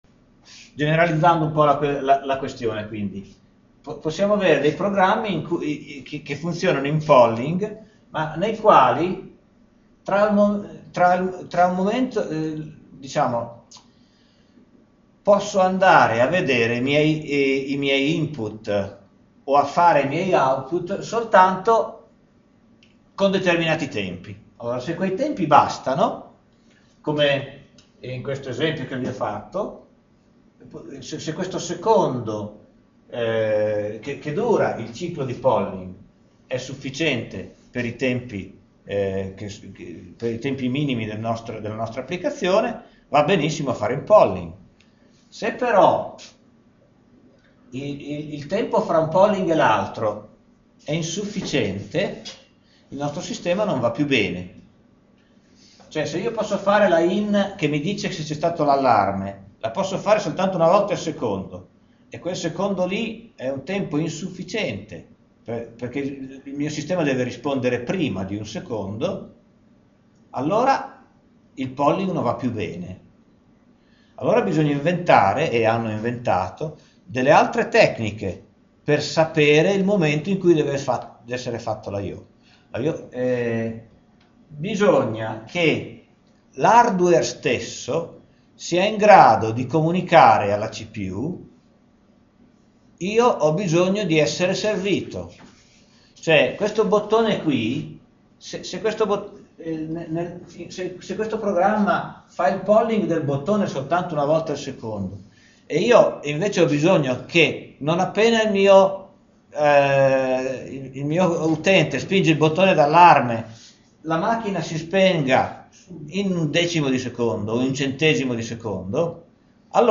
Lezione a 4 AS del 2007-10-12 (solo audio). 40 MByte Input output in interrupt , introduzione. Lezione a 4 AS del 2007-10-19 (solo audio, registrazione parziale). 17 MByte Interrupt vettorizzato.